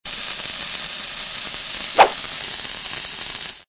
TL_dynamite.ogg